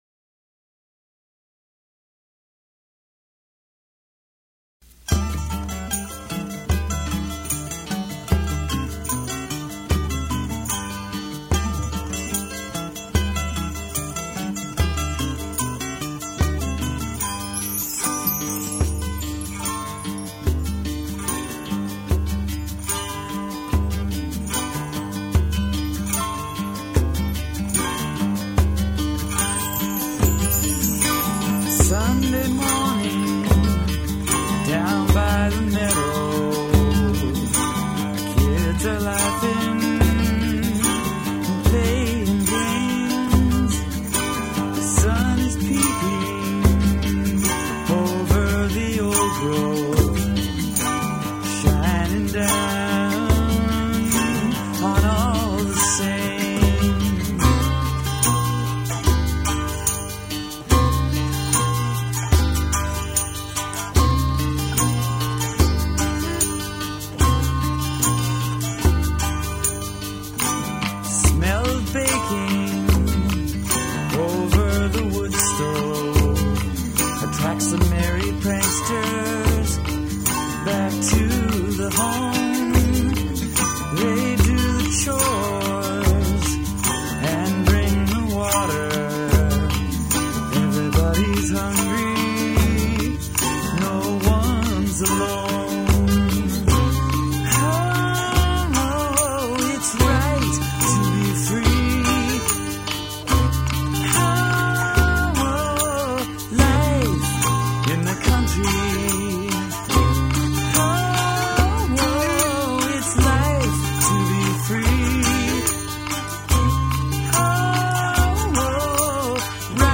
Vocals,Bass,mandolin
acoustic guitar
Campfire song